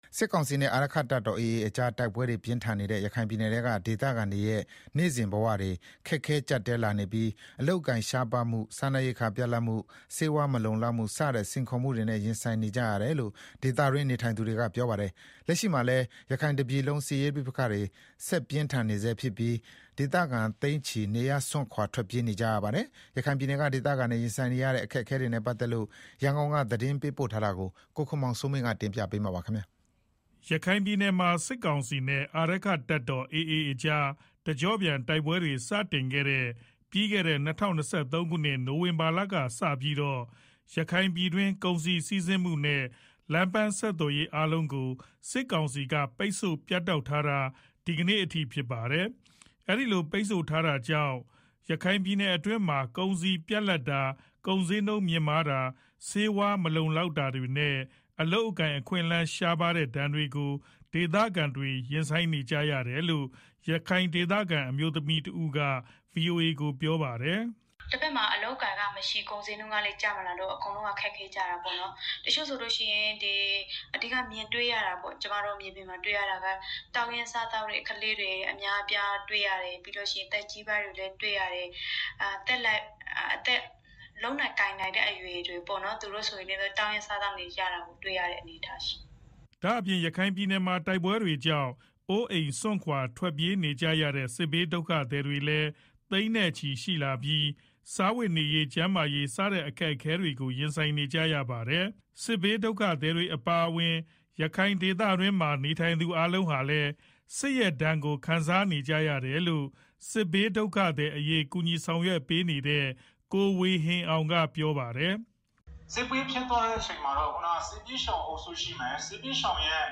စစ်ကောင်စီနဲ့ အာရက္ခတပ်တော် AA ကြား တိုက်ပွဲတွေပြင်းထန်နေတဲ့ ရခိုင်ပြည်နယ်ထဲက ဒေသခံတွေရဲ့ နေ့စဉ်ဘဝတွေ ခက်ခဲကြပ်တည်းလာနေပြီး အလုပ်အကိုင်ရှားပါးမှု စားနပ်ရိက္ခာပြတ်လပ်မှု ဆေးဝါးမလုံလောက်မှု စတဲ့ စိန်ခေါ်မှုတွေနဲ့ရင်ဆိုင်နေကြရတယ်လို့ ဒေသတွင်းနေထိုင်သူတွေကပြောပါတယ်။ လက်ရှိမှာလည်း ရခိုင်တပြည်လုံးစစ်ရေးပဋိပက္ခတွေ ဆက်ပြင်းထန်နေဆဲဖြစ်ပြီး ဒေသခံသိန်းချီ နေရပ်စွန့်ခွာထွက်ပြေးနေကြရပါတယ်။ ရန်ကုန်က သတင်းပေးပို့ထားပါတယ်။